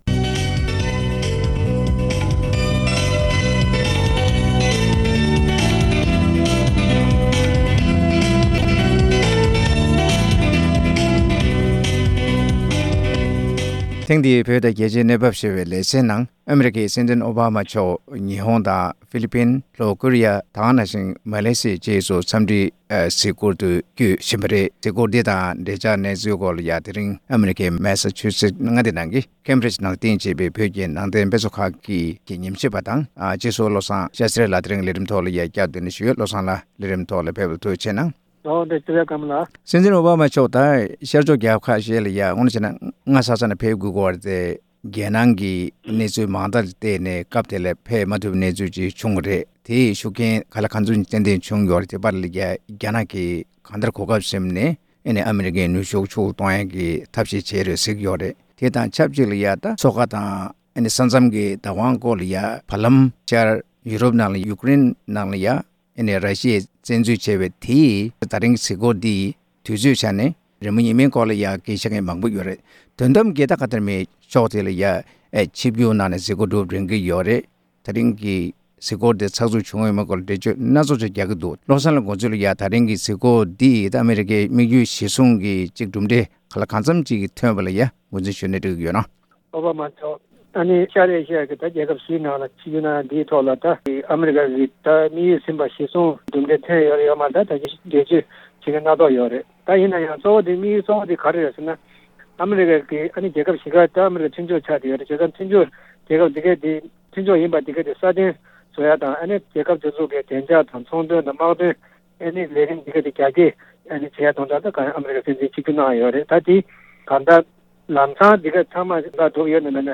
གླེང་མོལ་ཞུས་པ་ཞིག་གསན་གནང་གི་རེད༎